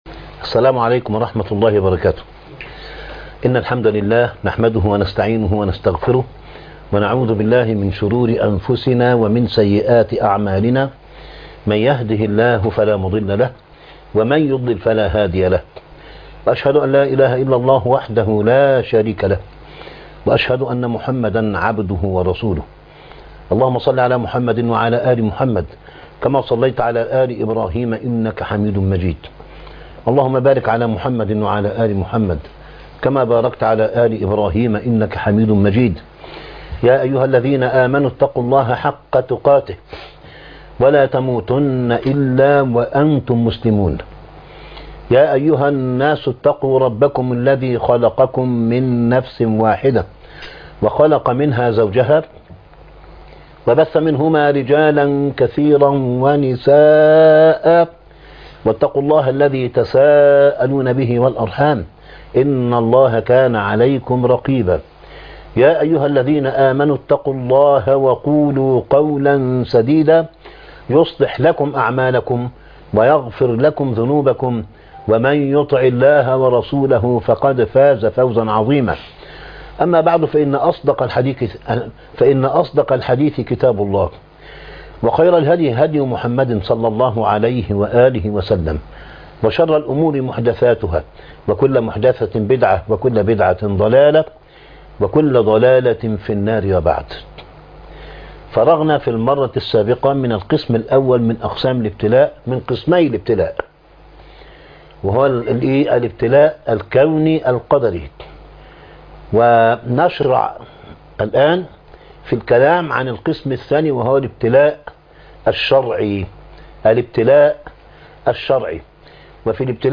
200 سؤال وجواب في العقيدة درس 32